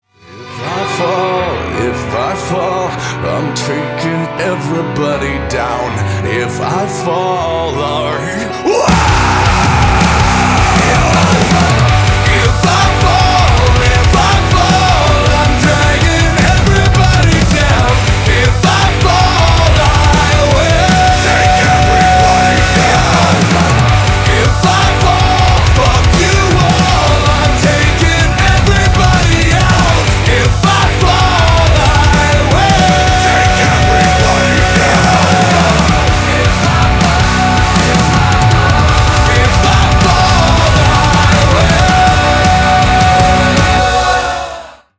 • Качество: 320, Stereo
громкие
мощные
брутальные
Alternative Metal
злые
heavy Metal
groove metal